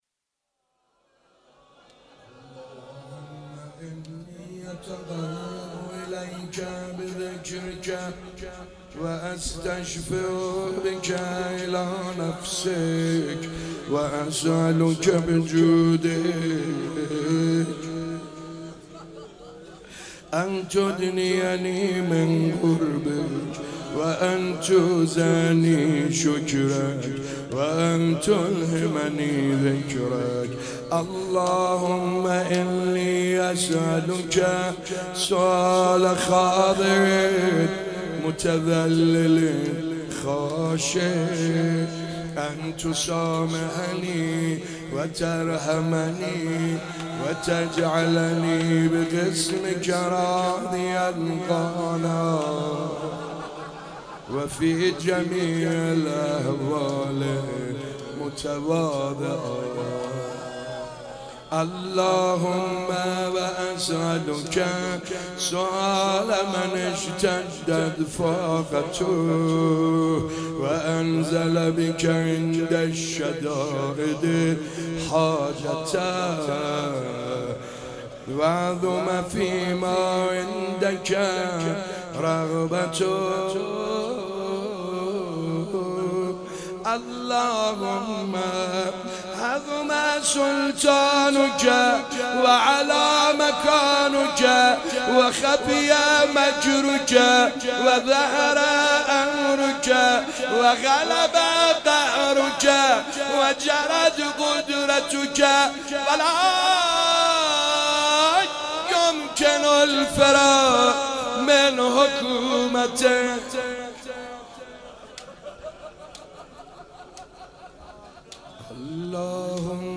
شب 7 ماه مبارک رمضان 96_دعا خوانی_اللهم انی اتقرب الیک